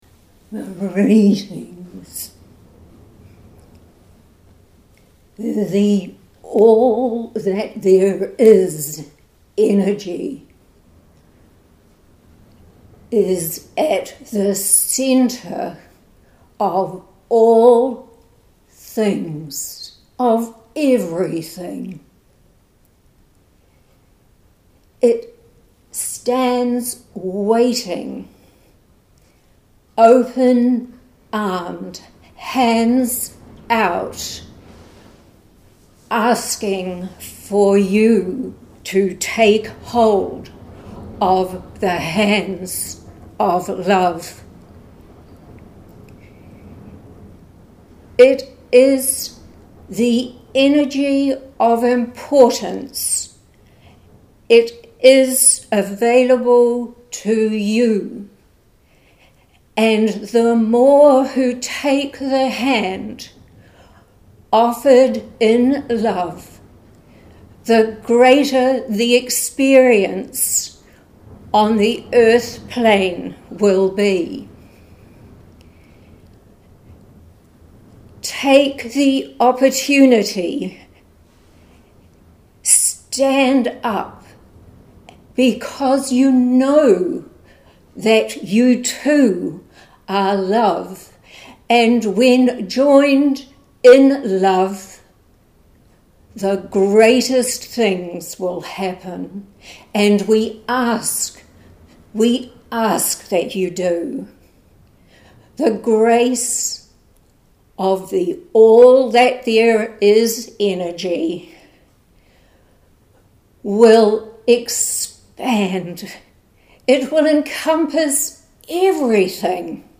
“The Hand of Love is Waiting” – audio recording of channelled message from The Circle of The Light of The Love Energy